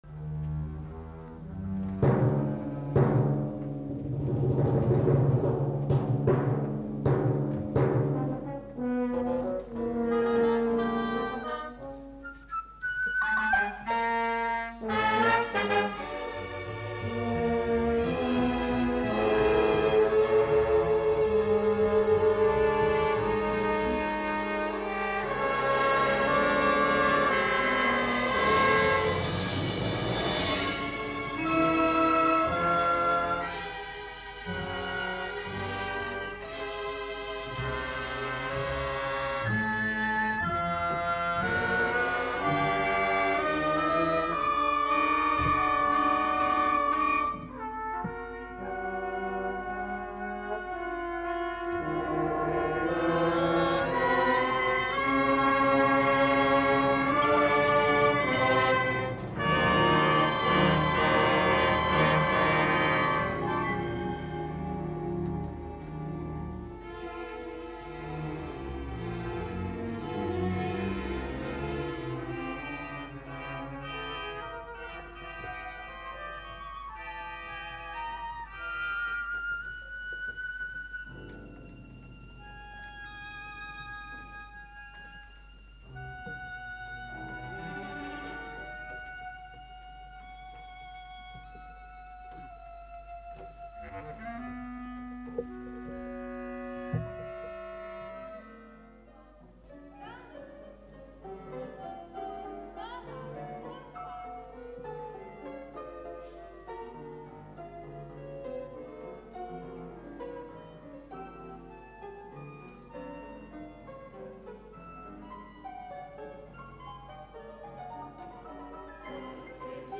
concerto dal vivo estratti vari
audio 44kz stereo